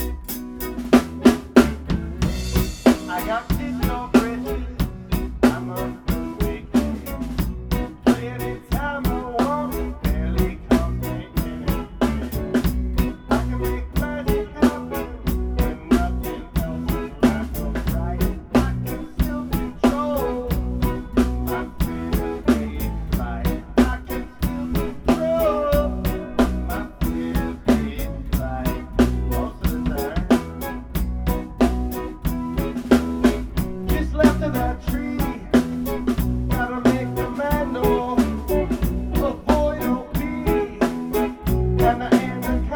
Keep in mind this is a live recording of a song that is not yet complete, with two musicians new to the band.